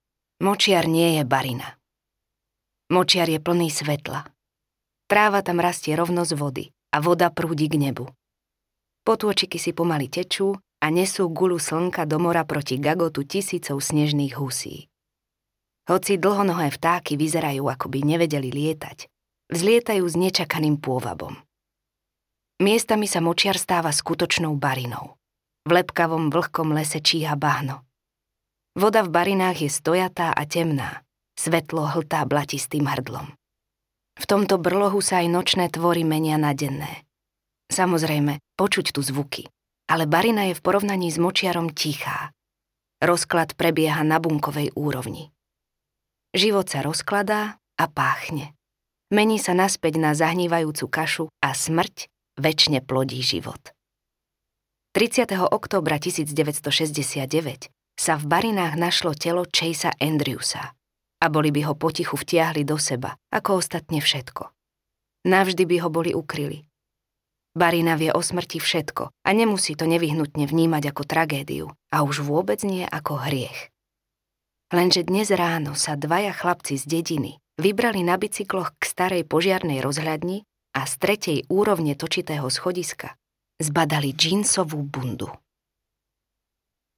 Kde raky spievajú audiokniha
Ukázka z knihy
• InterpretTáňa Pauhofová